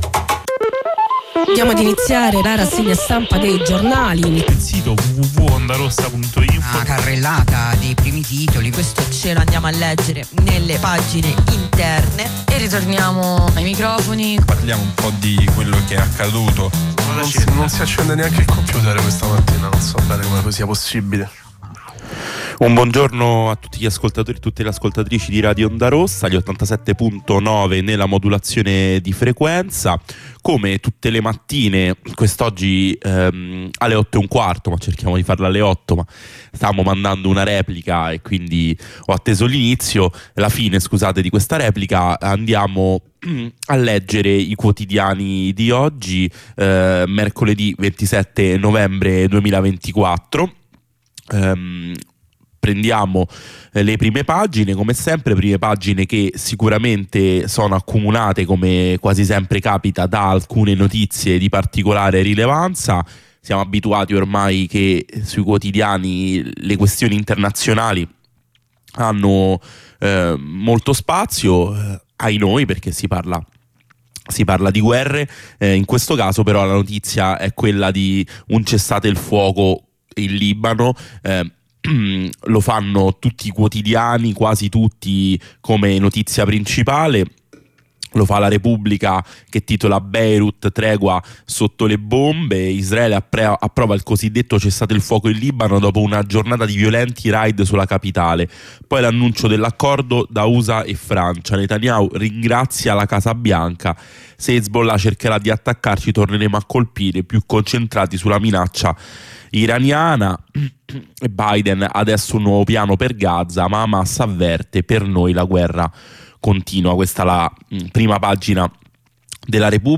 Lettura e commento dei quotidiani.